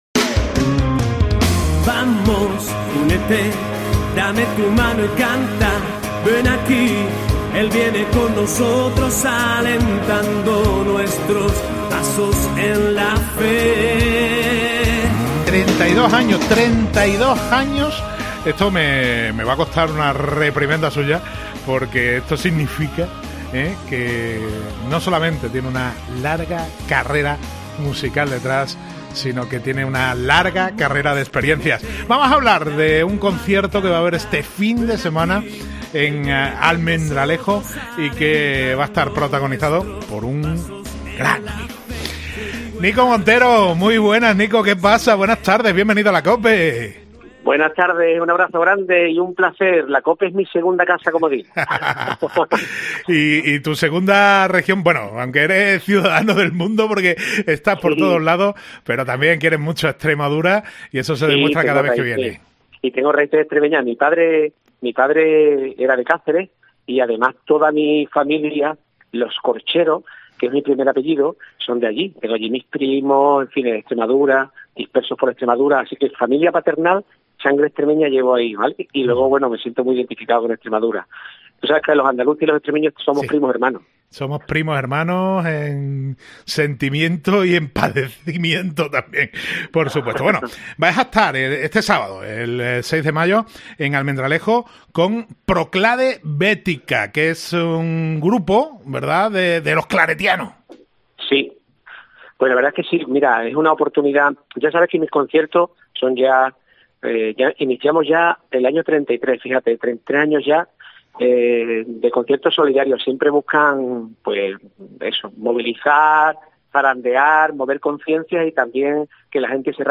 Hoy hemos tenido la oportunidad de charlar con él antes de su visita a Almendralejo este próximo sábado día 6, de la mano de Proclade Bética. Él, como siempre, actuará gratuitamente y la recaudación será destinada a uno de los proyectos de la ONG de los Misioneros Claretianos.